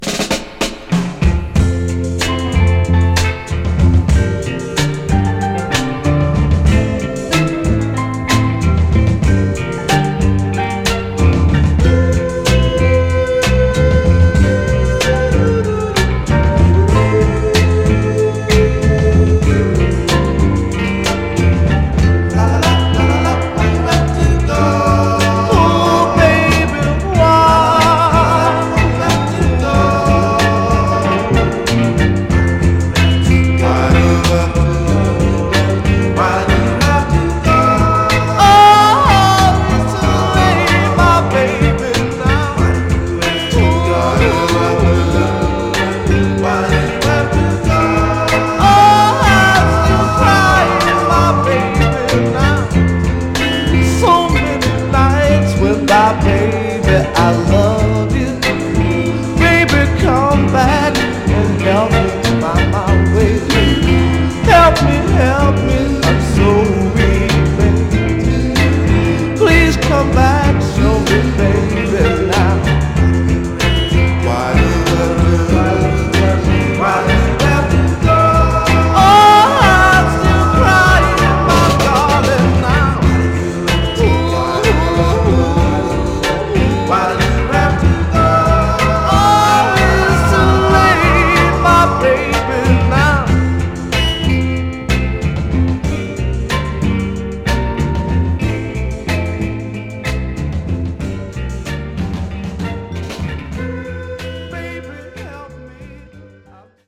ドゥー・ワップ仕込みの美しいヴォーカル・ワークが活きた、美しいメロウ・ノーザン・ソウルの名作です！
※試聴音源は実際にお送りする商品から録音したものです※